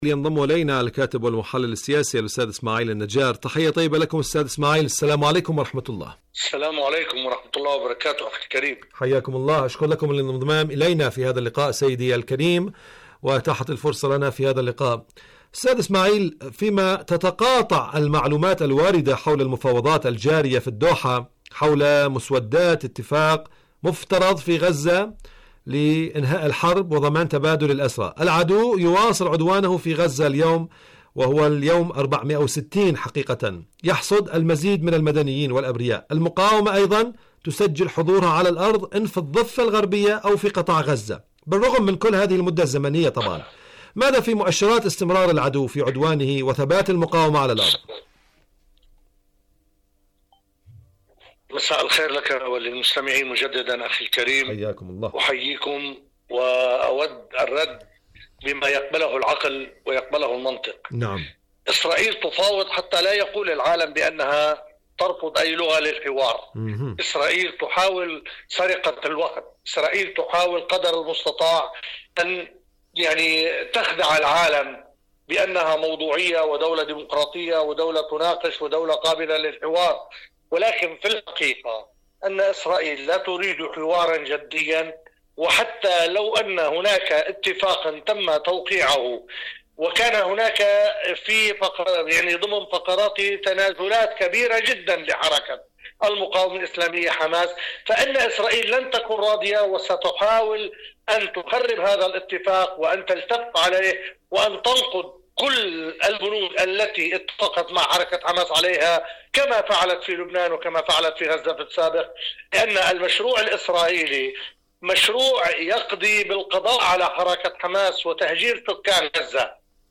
إذاعة طهران- حدث وحوار: مقابلة إذاعية